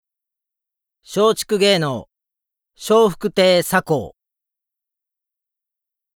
ボイスサンプル ＜クレジット＞